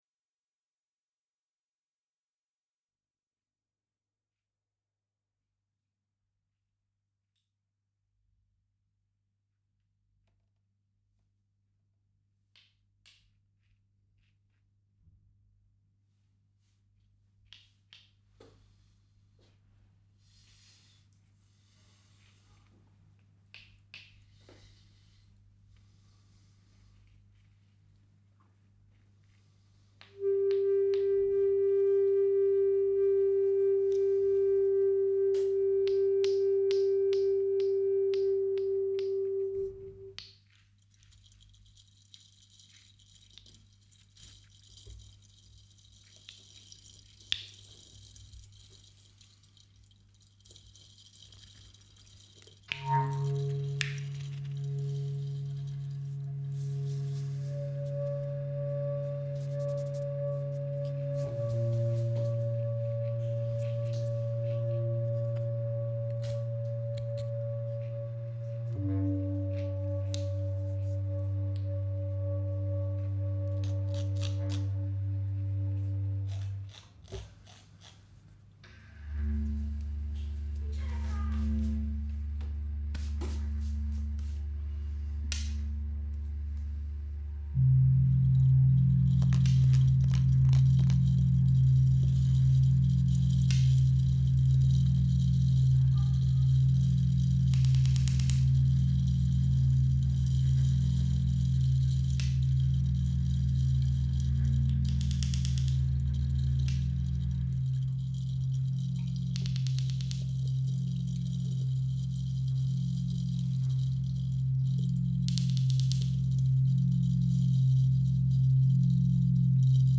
International Publisher and label for New experimental Music